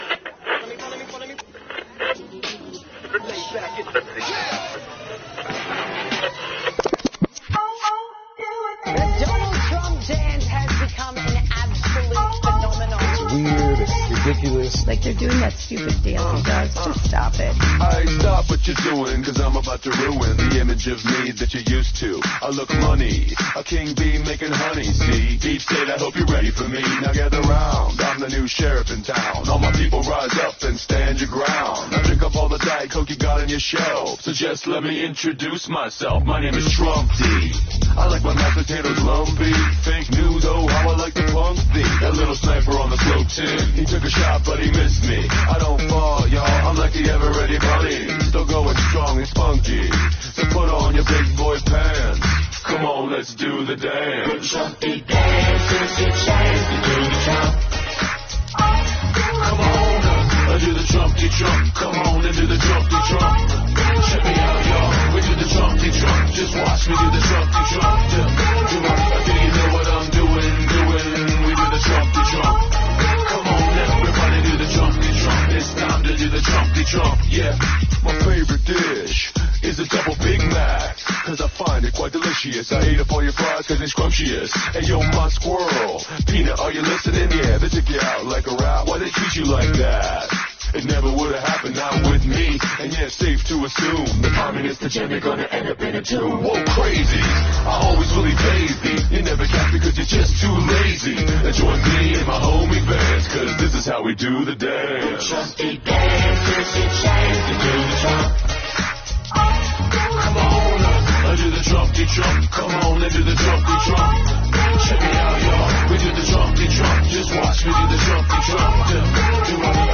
➡ This text seems to be a lively and energetic song about a dance called the “chunky jump” or “Chomp-de-Chomp”. The singer encourages everyone to join in, claiming it’s a fun and easy dance that everyone can do. The song also includes some playful banter and boasts about the singer’s resilience and love for fast food.